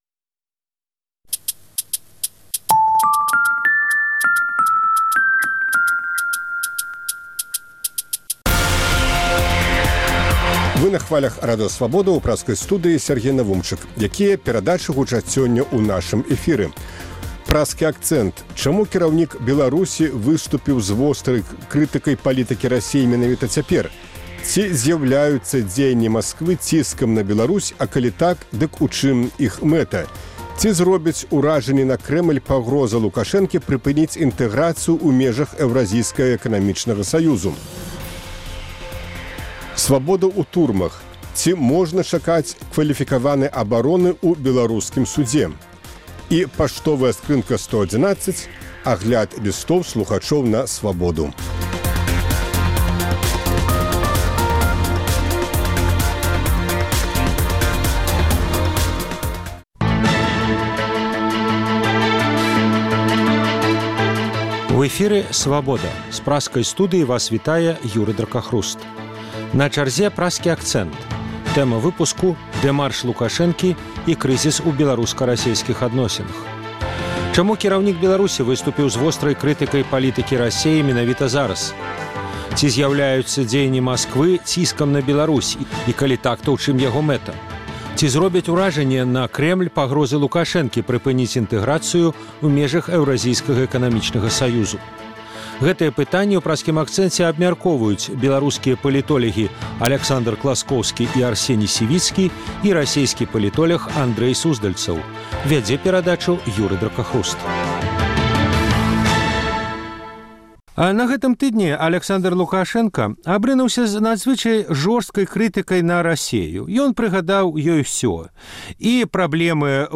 абмяркоўваюць беларускія палітолягі